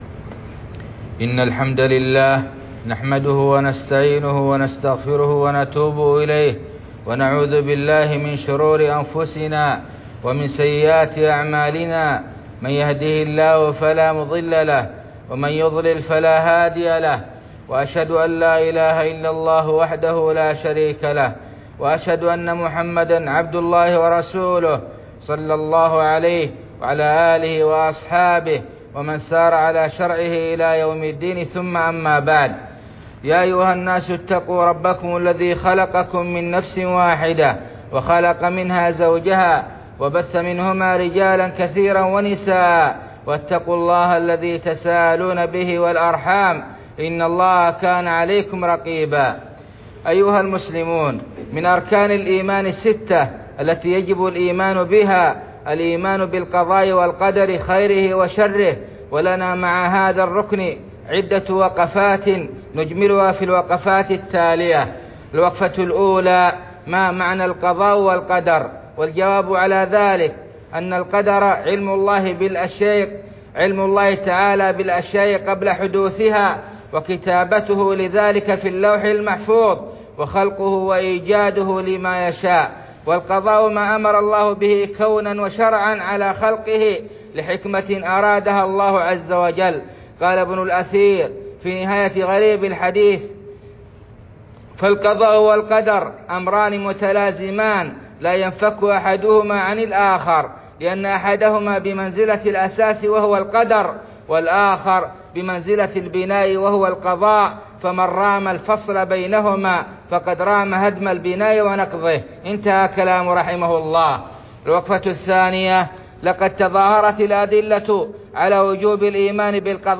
خطبة
خطب الجمعة والأعياد